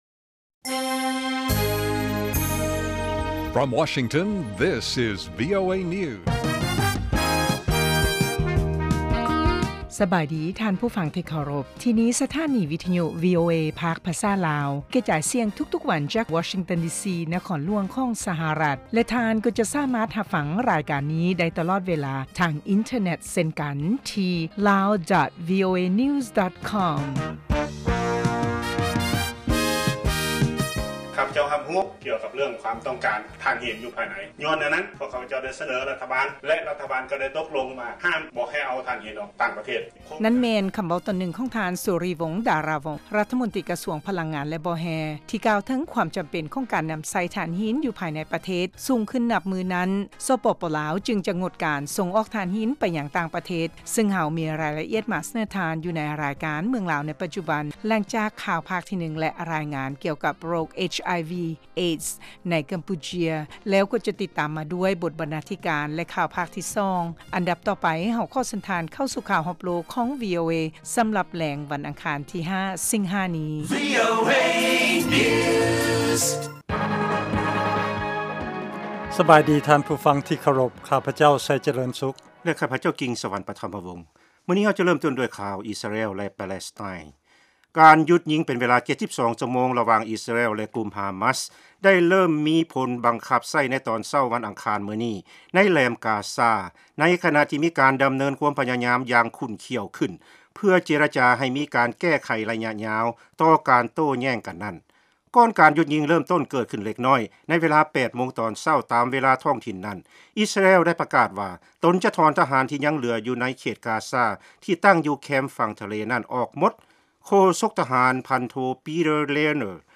ພວກເຮົາສະເໜີຂ່າວ ຂໍ້ມູນ ແລະລາຍງານທີ່ໜ້າສົນໃຈ ກ່ຽວກັບເຫດການທີ່ເກີດຂຶ້ນໃນປະເທດລາວ ສະຫະລັດອາເມຣິກາ ເອເຊຍແລະຂົງເຂດອື່ນໆຂອງໂລກ ຕະຫລອດທັງບົດຮຽນພາສາອັງກິດ ແລະລາຍການເພງຕາມຄຳຂໍຂອງທ່ານຜູ້ຟັງ. ຕາລາງເວລາອອກອາກາດ ທຸກໆມື້ ເວລາທ້ອງຖິ່ນໃນລາວ 07:30 ໂມງແລງ ເວລາສາກົນ 1230 ຄວາມຍາວ 00:30:00 ຟັງ: MP3